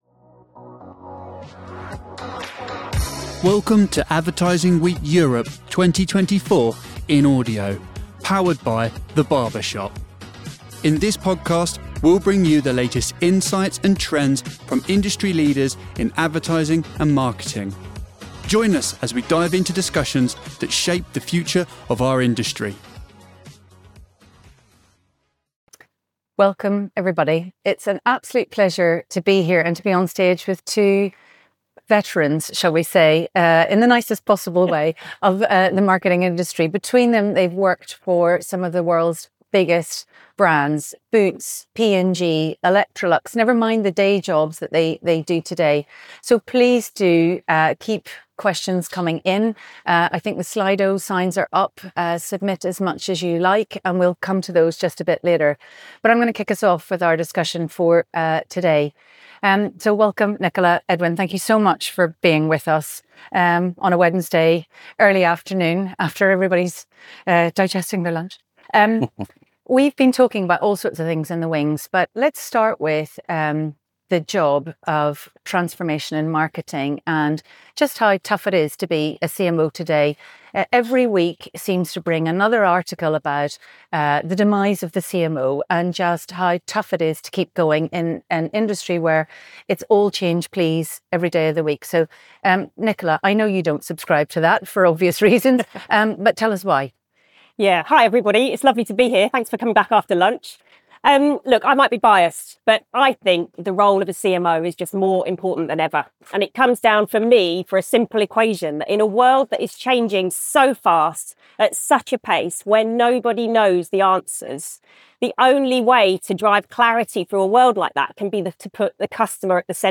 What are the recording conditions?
Advertising Week Europe 2024 in Audio